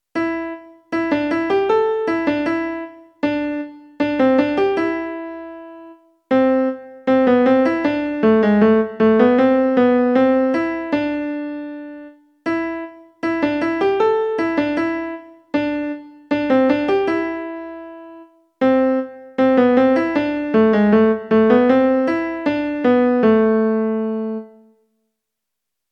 作例は１小節のフレーズを変化させ、４小節で一纏めにします。また、４小節のまとめをもう一回少し変化させて繰り返し、８小節まで伸ばしています。
gibri_melody.mp3